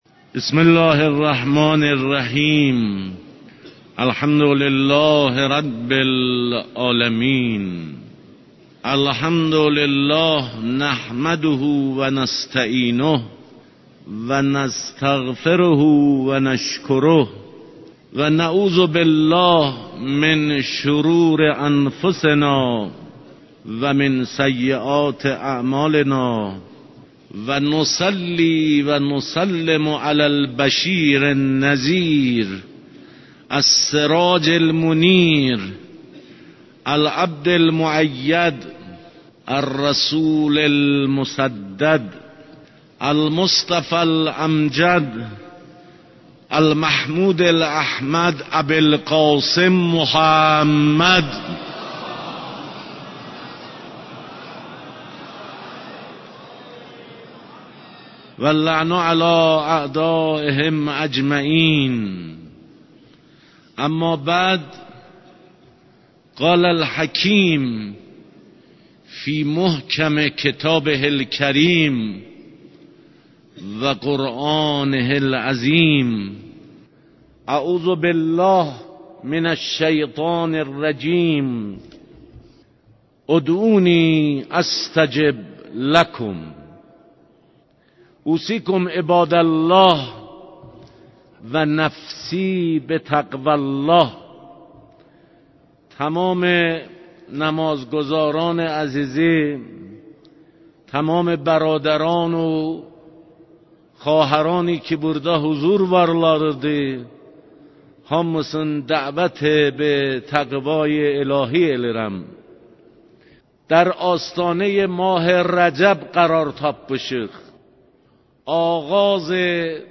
Təbrizin Cümə Namazınız Xütbələri 12 FEVRAL 2021